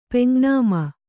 ♪ peŋg nāma